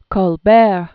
(kôl-bĕr, kōl-), Claudette Originally Lily Claudette Chauchoin. 1903-1996.